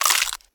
horror
Flesh Bite Crunch